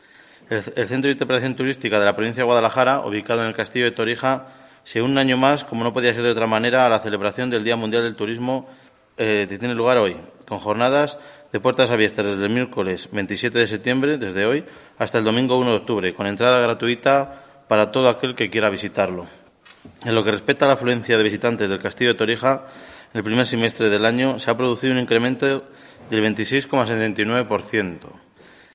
Precisamente, coincidiendo hoy con el Día Mundial del Turismo, el diputado de Turismo, Jesús Parra, ha anunciado novedades en el Centro de Interpretación Turística de la Provincia de Guadalajara, ubicado en el Castillo de Torija, para celebrarlo.